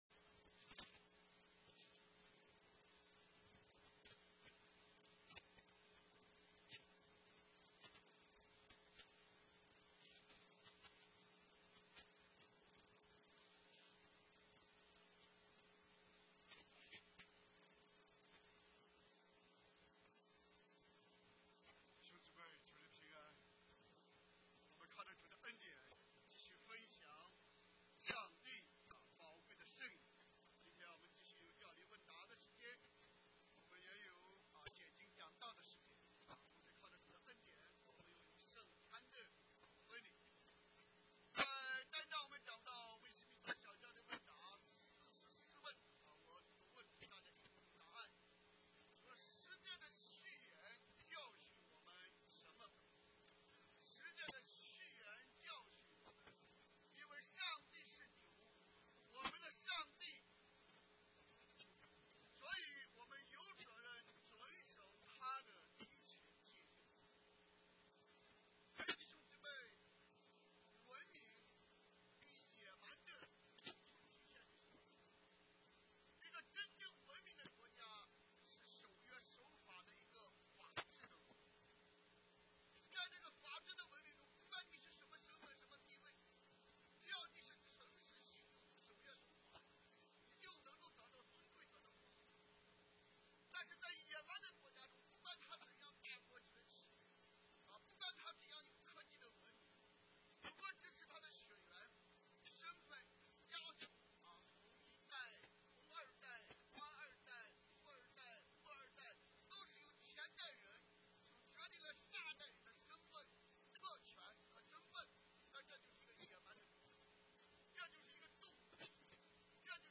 Sermons – Page 82 – 主恩基督教會